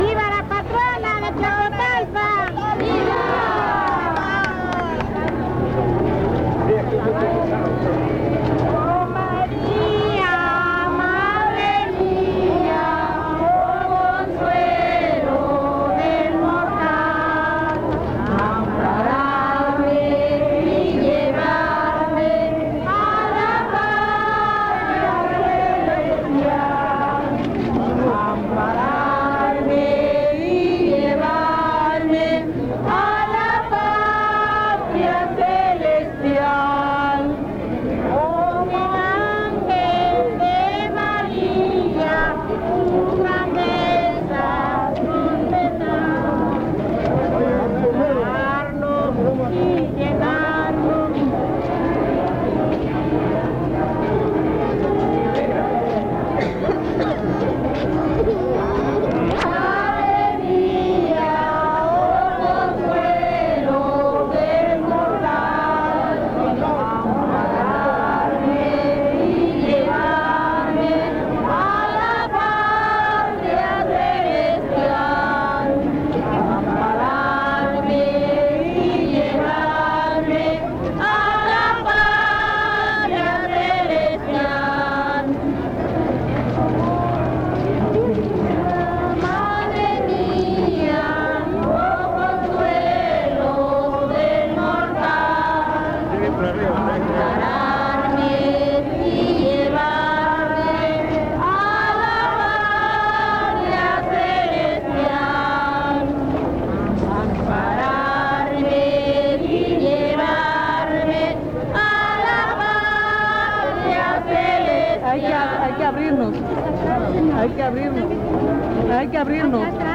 Fiesta de la Candelaria